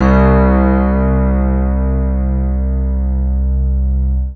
Index of /90_sSampleCDs/Zero G Creative Essentials Series Vol 26 Vintage Keyboards WAV-DViSO/TRACK_21